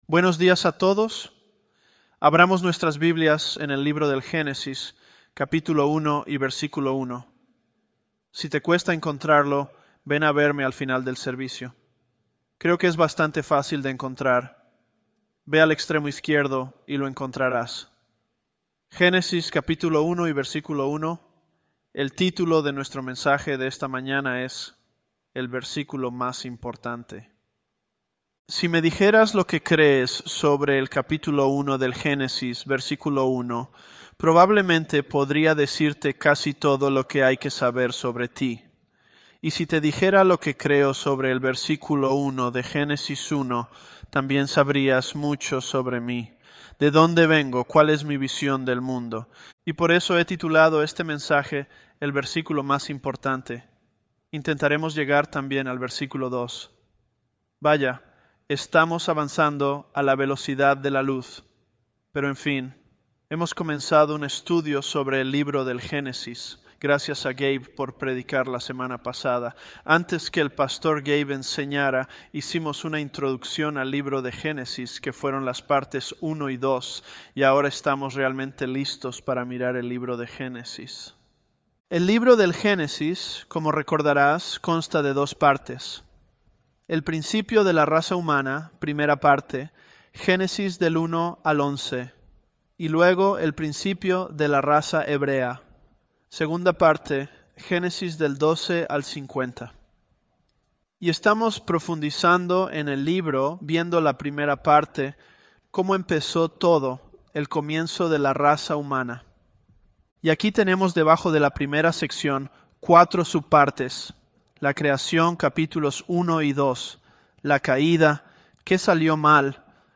ElevenLabs_Genesis-Spanish003b.mp3